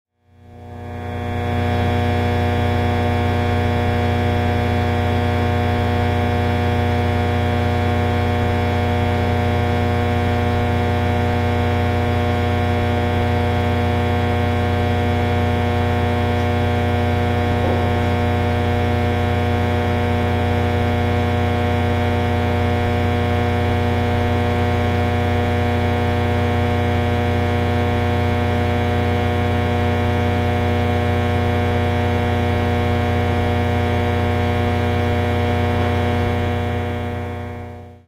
Звук высокого напряжения